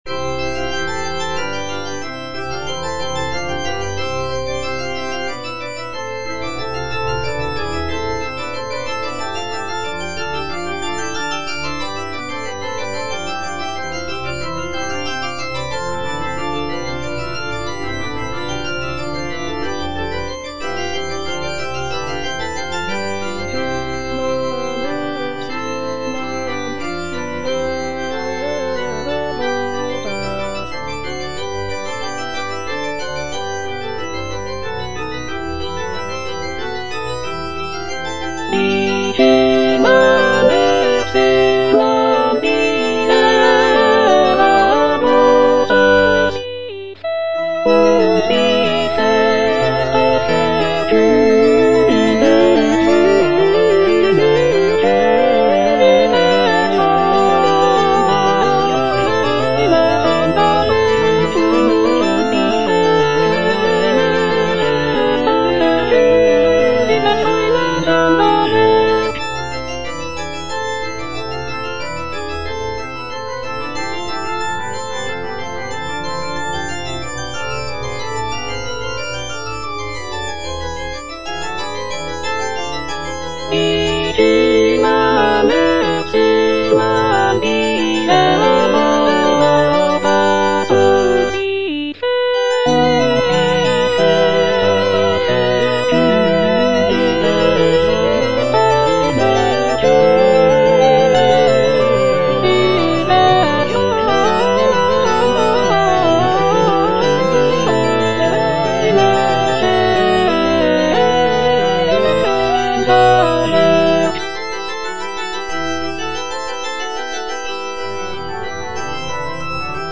Choralplayer playing Cantata
The work features intricate choral writing, beautiful melodies, and rich orchestration, showcasing Bach's mastery of baroque music composition."